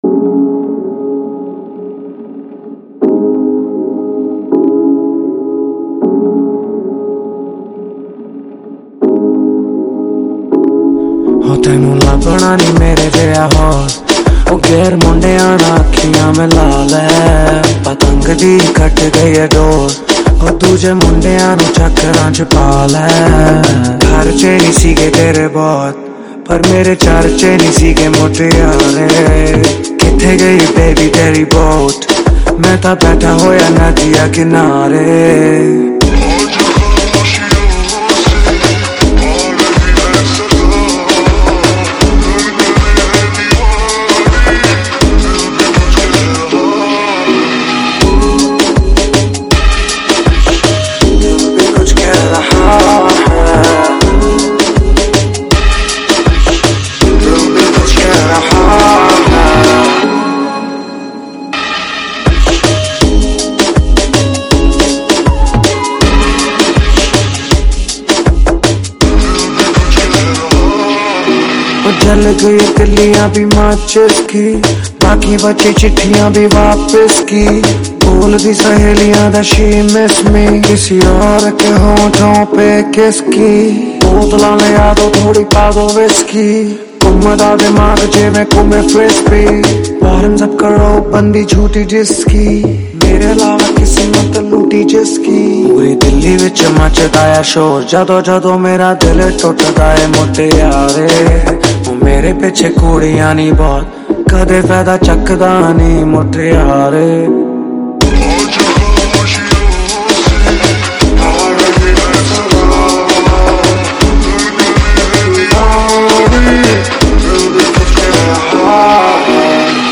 Category: Single Songs